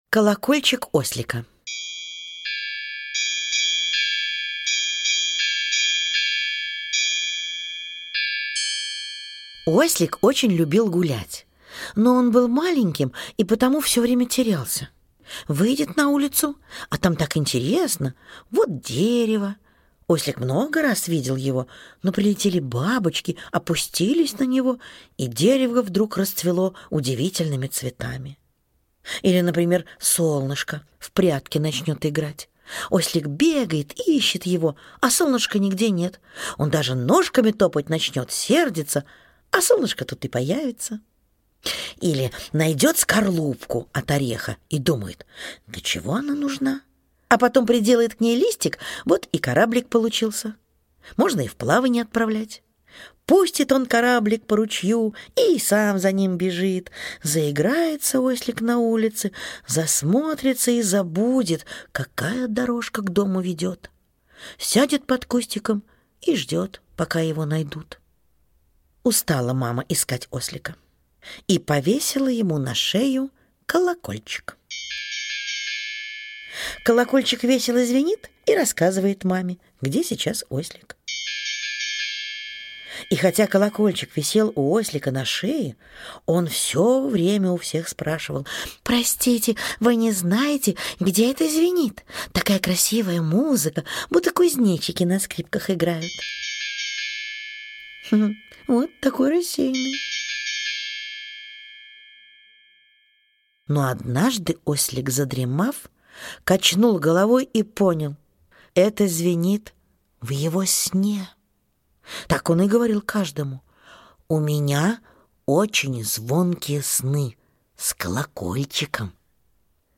Аудиосказка «Колокольчик ослика»